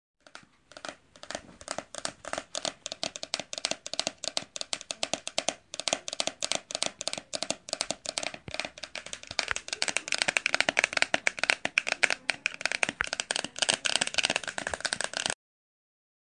我会警告你，由于马的呼吸是重复的，所以很明显是循环的。
Tag: thorobred 动物 运行 奔腾 现场记录